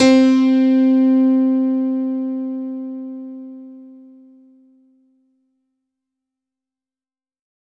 C3  DANCE -L.wav